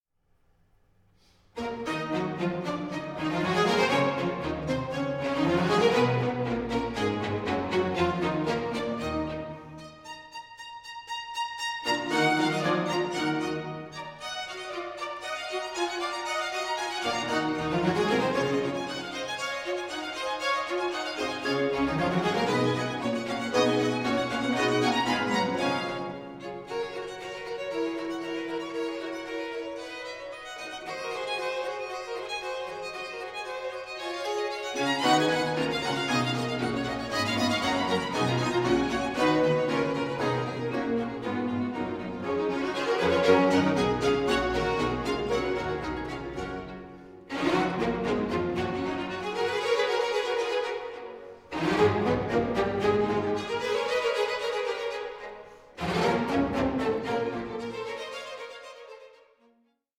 With their historically-informed and lively playing